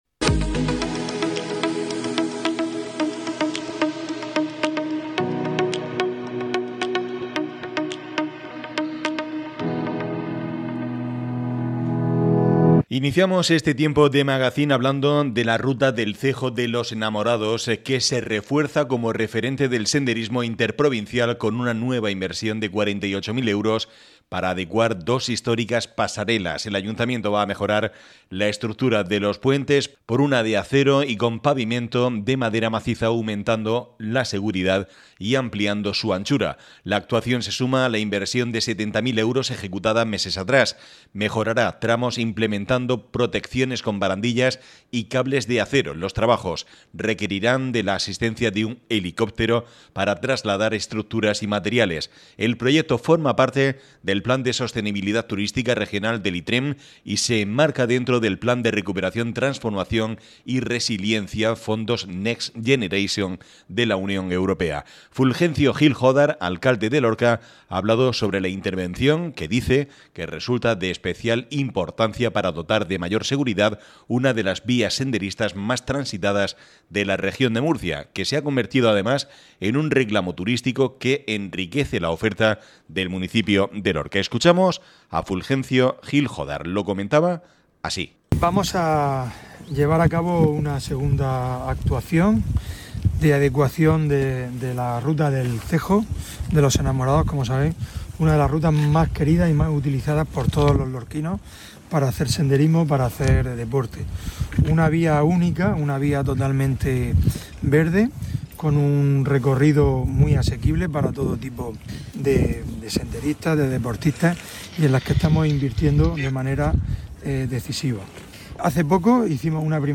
Escucha aquí las declaraciones del alcalde de Lorca Fulgencio Gil